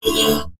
文件 文件历史 文件用途 全域文件用途 Enjo_dmg_02_2.ogg （Ogg Vorbis声音文件，长度0.6秒，152 kbps，文件大小：11 KB） 源地址:地下城与勇士游戏语音 文件历史 点击某个日期/时间查看对应时刻的文件。